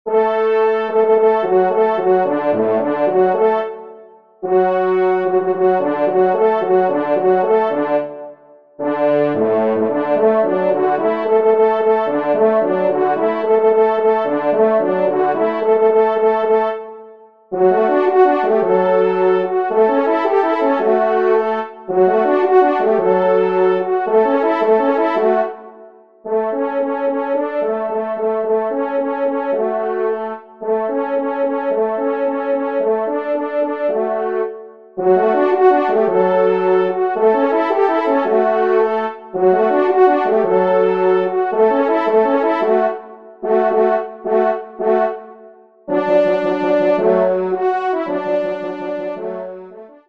4e Trompe